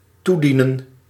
Ääntäminen
IPA : /ədˈmɪnɪstɚ/